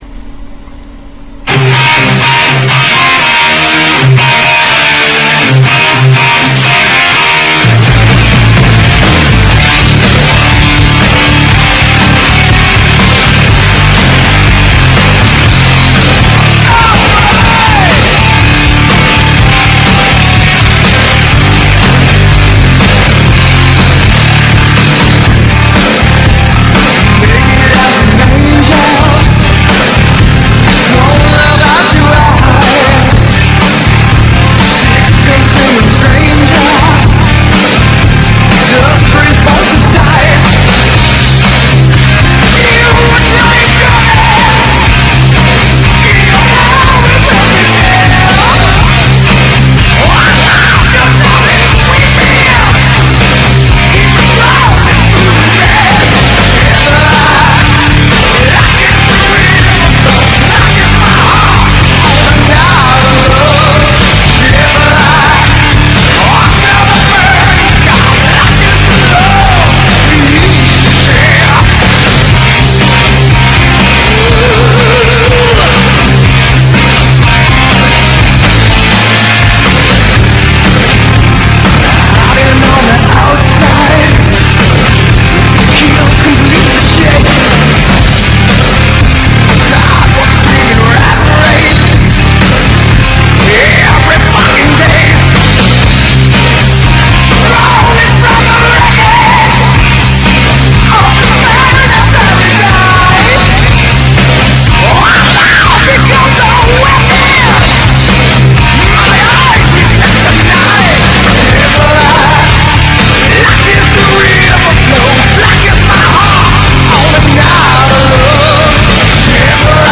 But it's a great hard rock album!
vocals, keyboard
guitar
bass guitar
drums